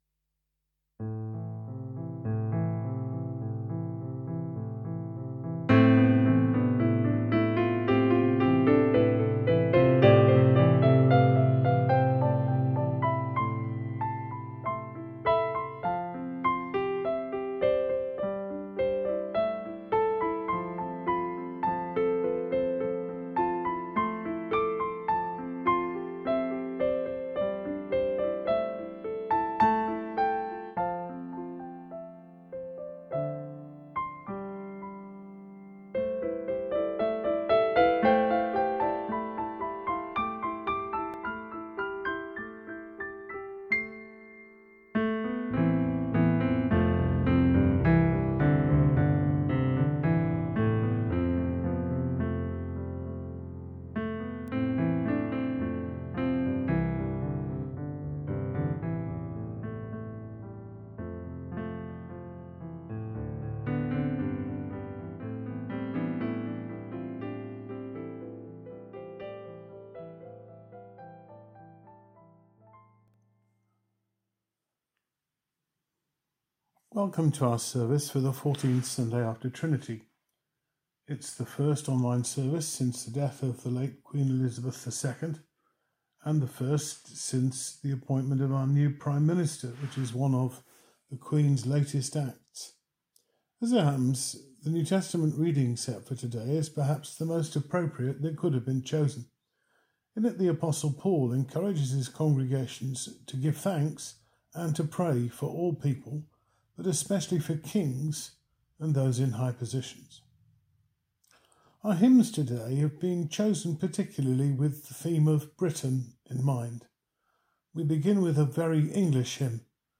Trinty 14 (18 September) 2022 Online Service